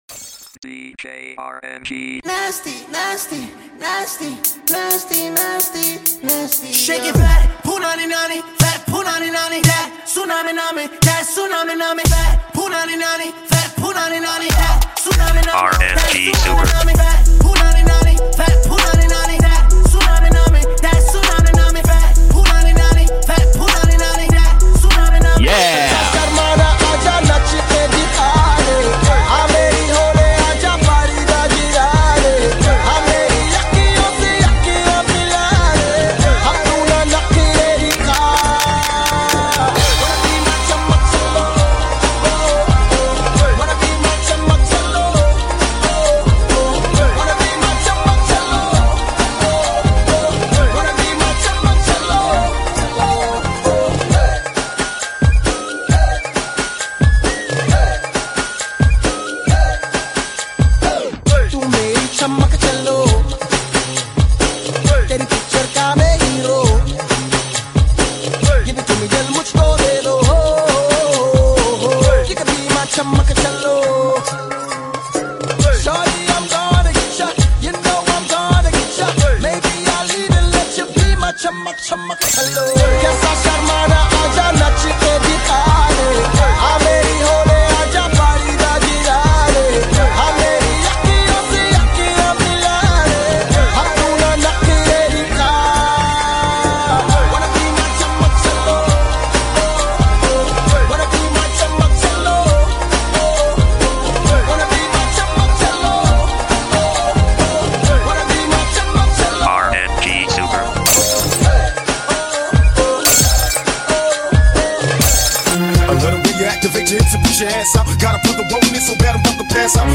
High quality Sri Lankan remix MP3 (6.5).